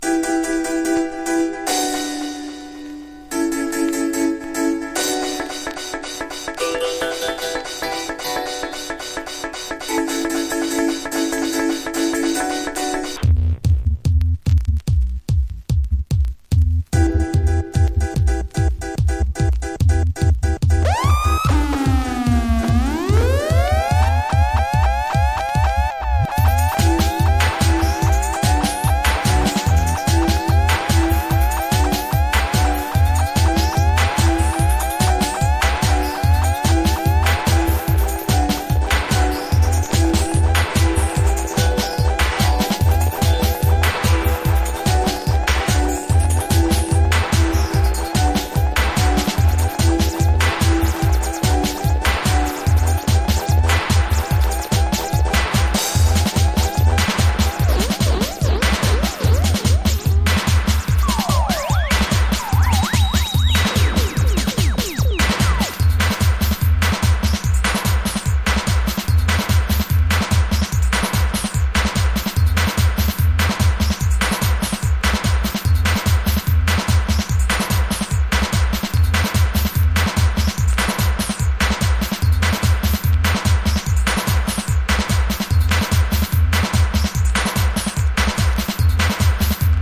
ルーツからジャングルまでバラエティ豊かな全13曲収録！